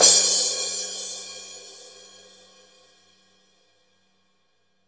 16 CRASH.wav